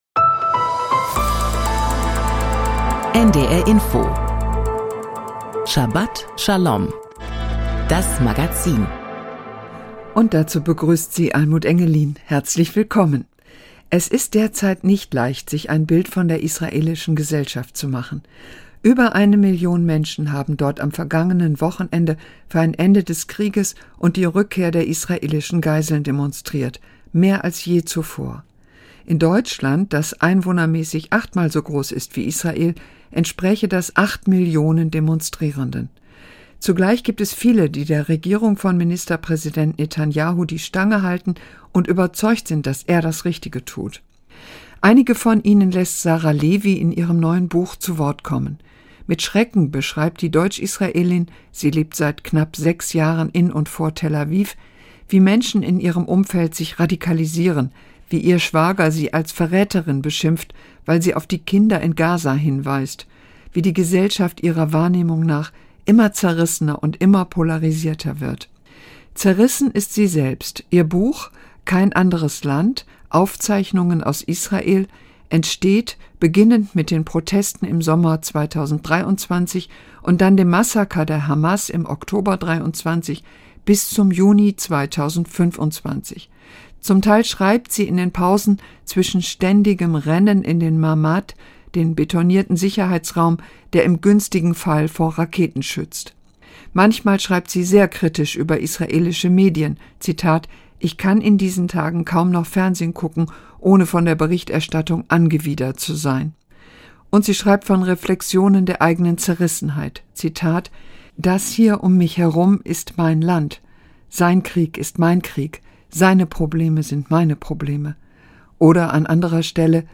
Kein anderes Land - Aufzeichnungen aus Israel Interview